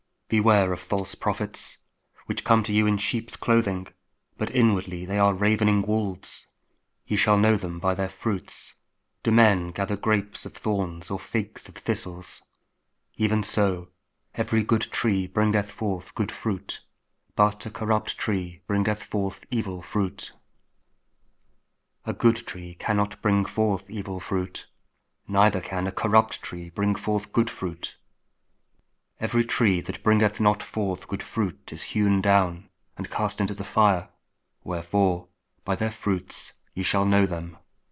Matthew 7: 15-20 – Week 12 Ordinary Time, Wednesday (King James Audio Bible KJV, Spoken Word)